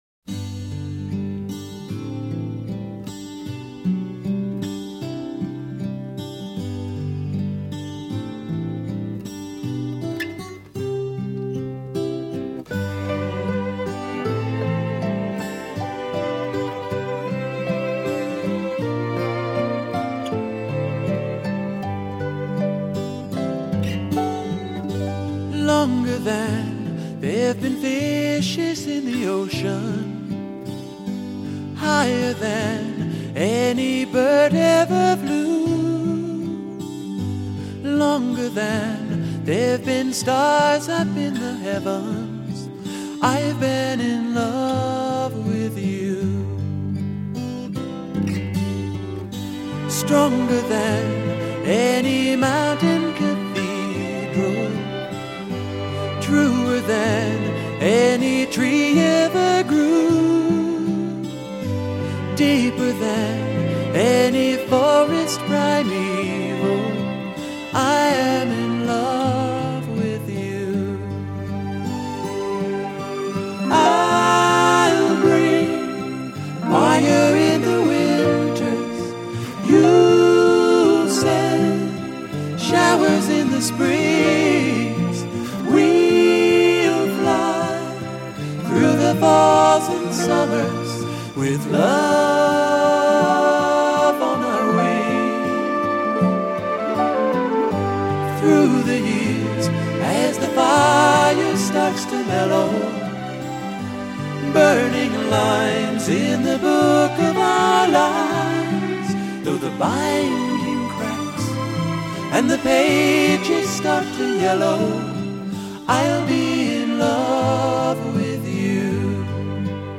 音樂類型：西洋音樂
◎以輕柔的歌喉、清澈的吉他聲及動人的詞句走紅樂壇的"民謠詩人"最新精選